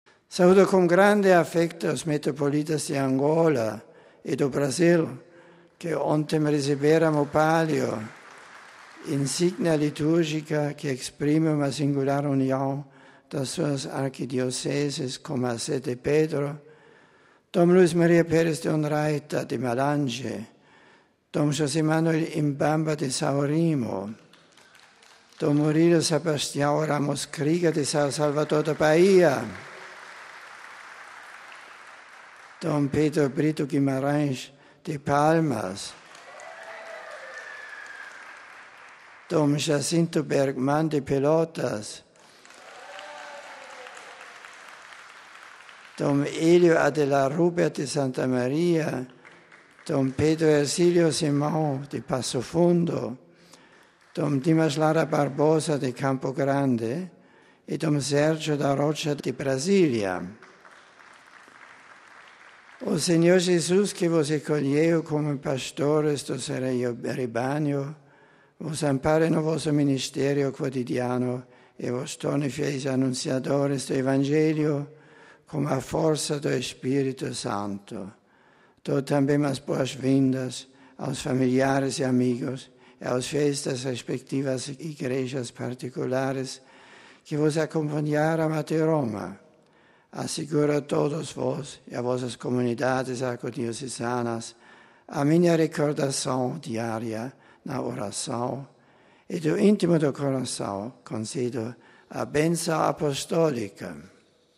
Escutemos Bento XVI falando em português RealAudio